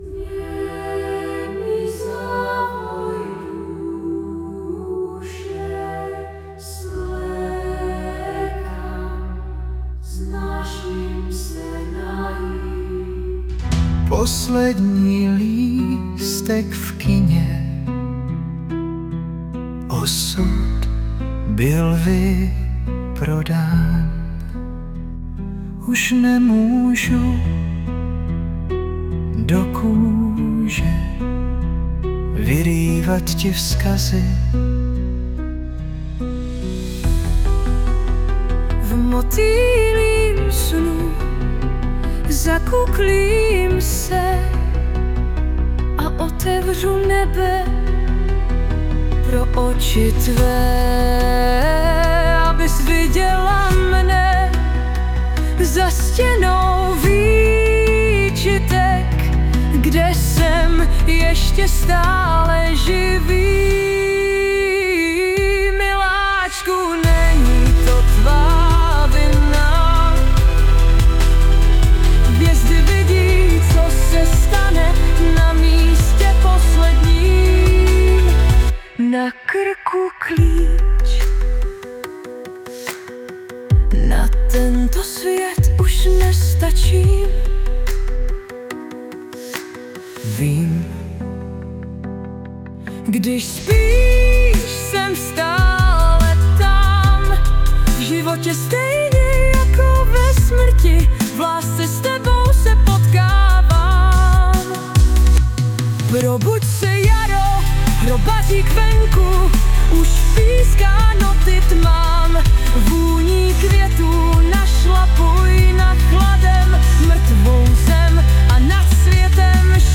2025 & Hudba, Zpěv a Obrázky: AI